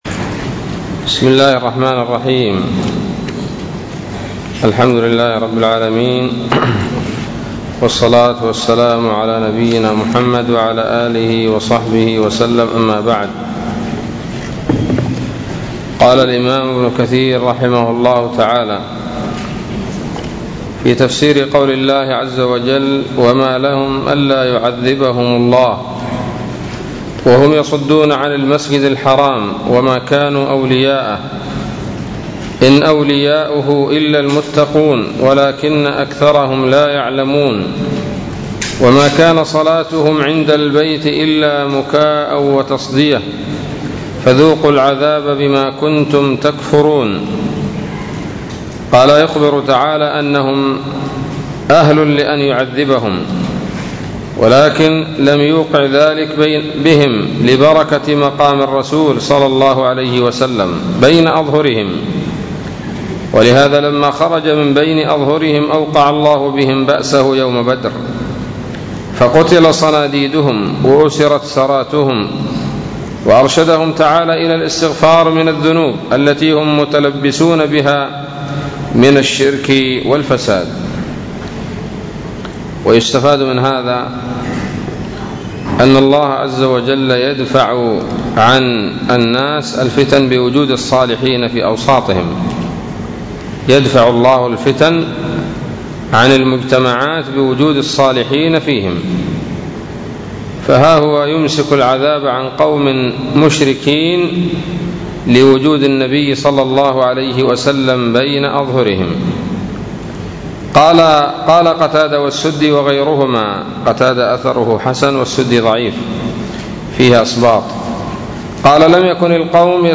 الدرس التاسع عشر من سورة الأنفال من تفسير ابن كثير رحمه الله تعالى